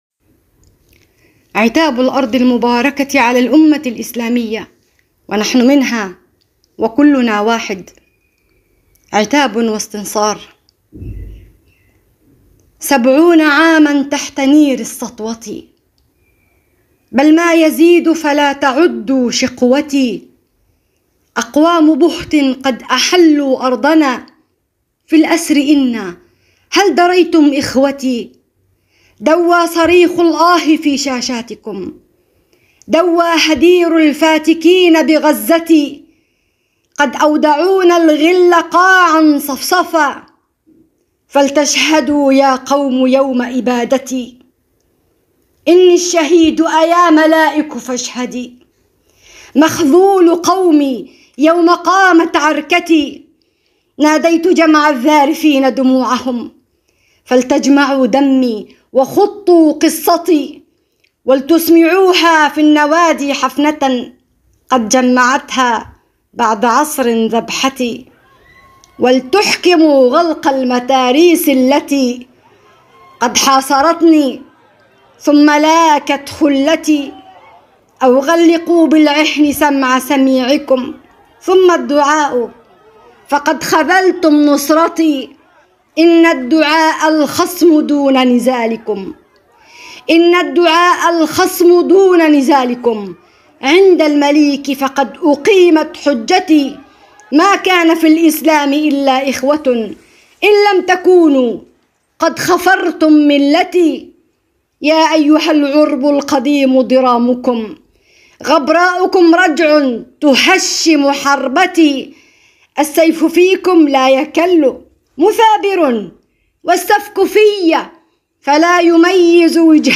أبيات شعرية - عتاب واستنصار من الأرض المباركة